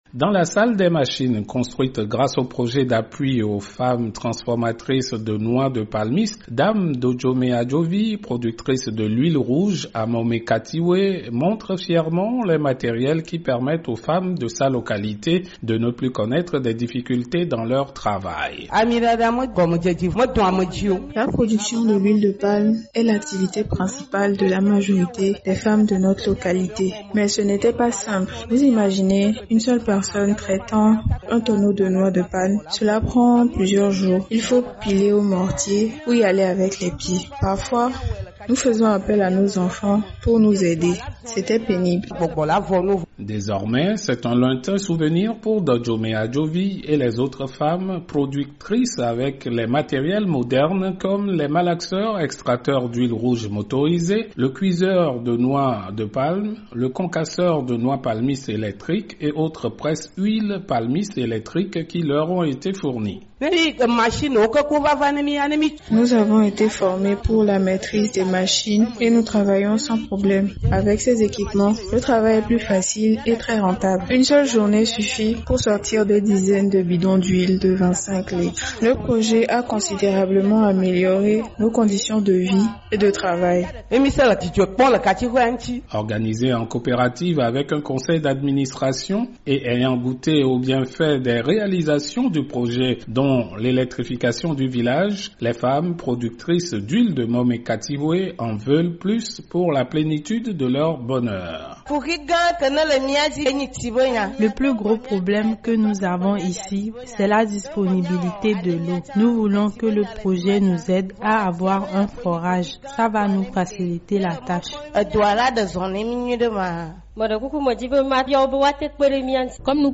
Au Togo, dans le village de Momé- Katihoé dans le sud du pays, le projet ‘’Appui aux femmes transformatrices de noix de palmiste’’ a transformé la vie de milliers de femmes, productrice de l’huile rouge. De Lomé, le reportage de notre correspondant